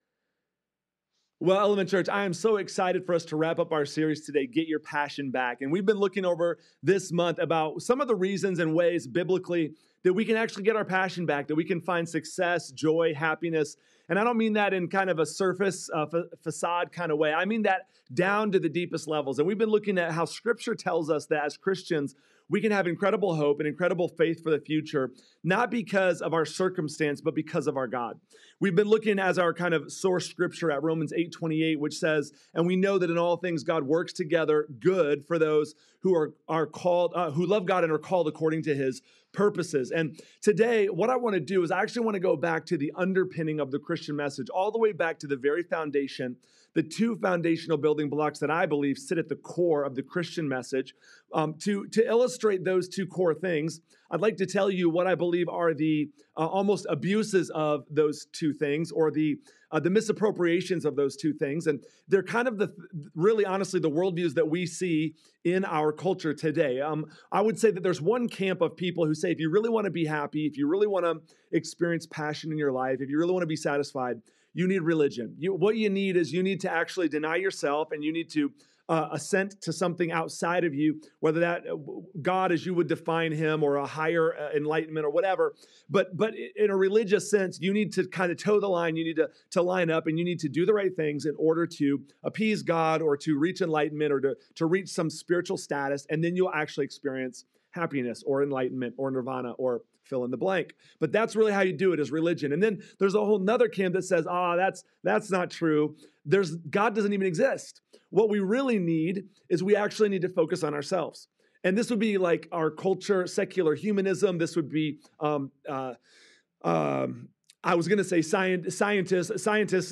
(Teaching) Get Your Passion Back | Part 4 | Grace & Truth